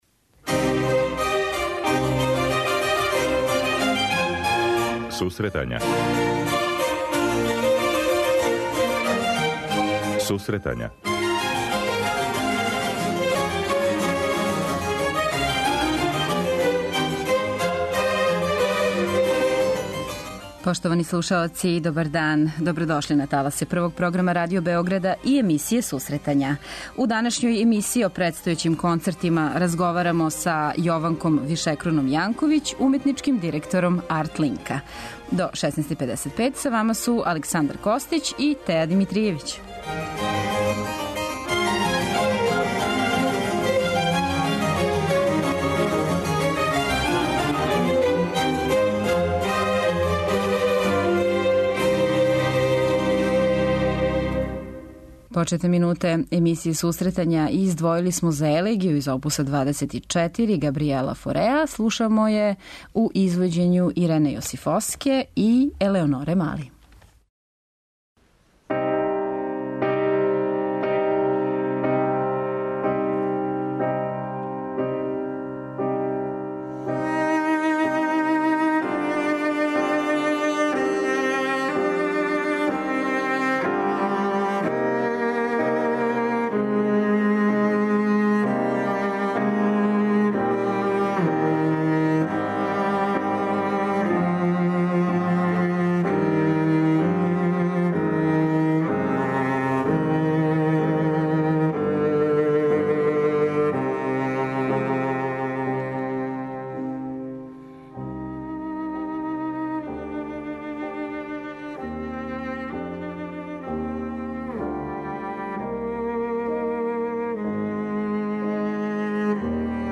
преузми : 26.37 MB Сусретања Autor: Музичка редакција Емисија за оне који воле уметничку музику.